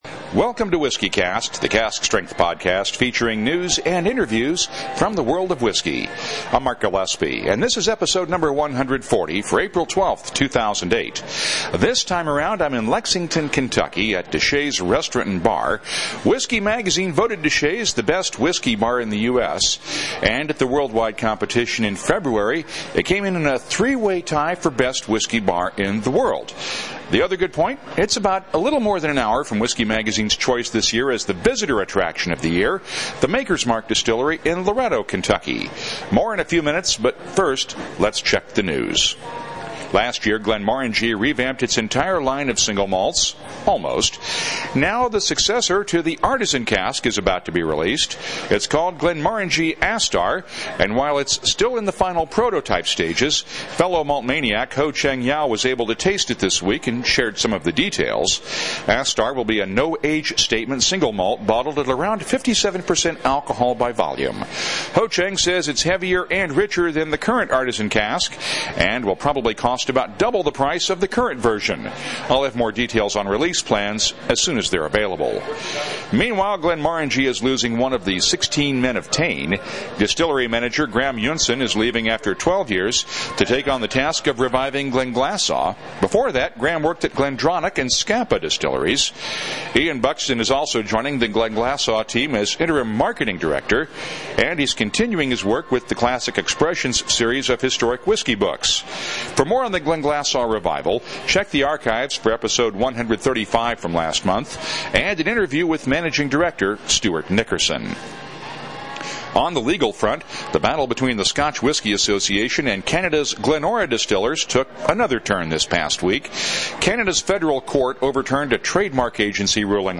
Time for another road trip…this time to the spiritual home of Bourbon…Kentucky. This week’s episode comes from DeSha’s Restaurant and Bar in Lexington, which tied for top honors as Best Whisky Bar in the World in Whisky Magazine’s recent Icons of Whisky Awards.